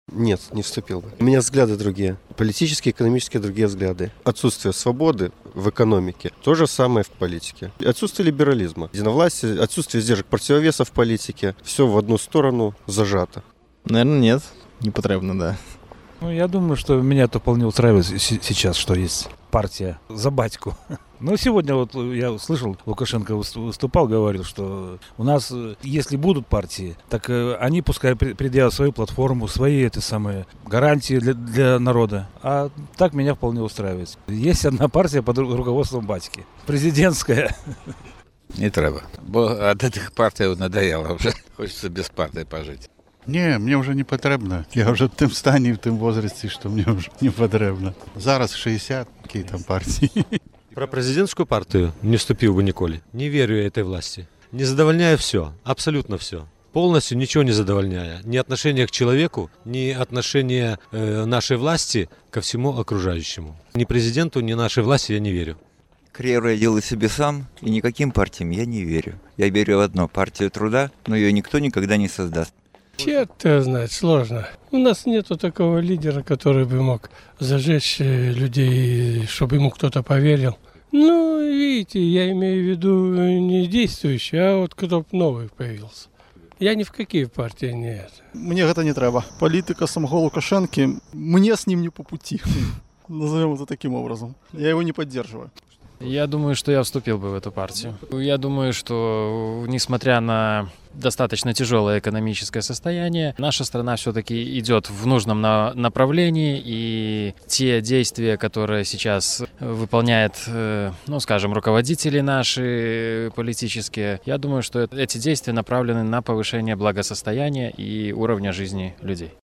Ці ўступілі б вы ў кіроўную палітычную партыю? Адказваюць гарадзенцы